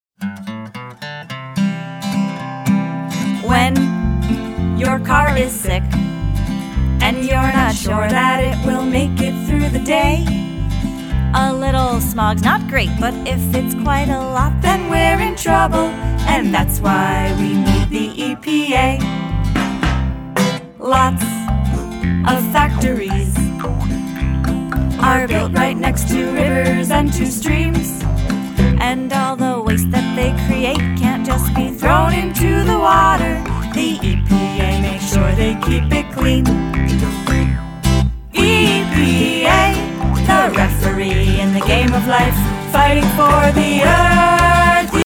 All tracks except Radio Edits include scripted dialogue.